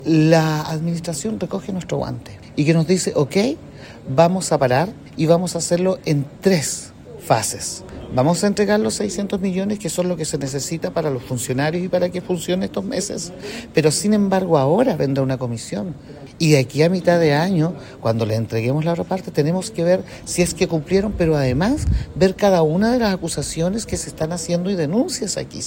Así lo dijo el concejal socialista, Esteban Barriga, indicando que también se deben investigar las irregularidades que denunciaron los otros ediles.